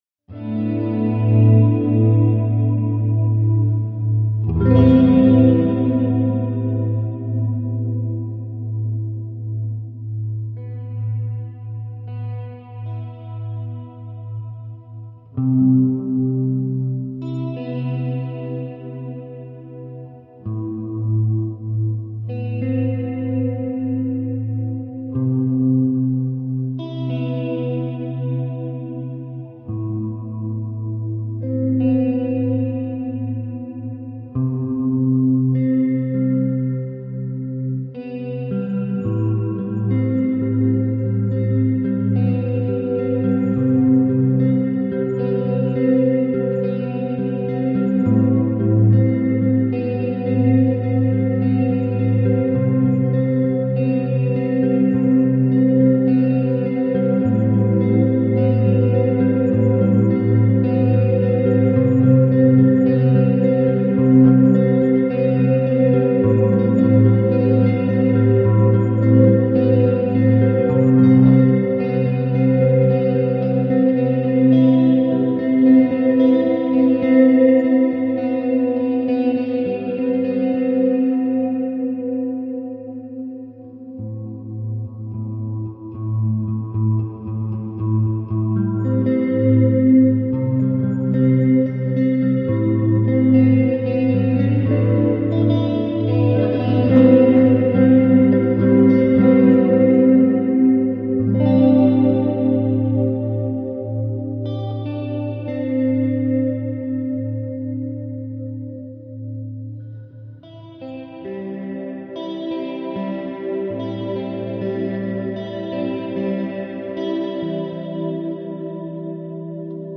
Моя вечерняя медитация с гитарой.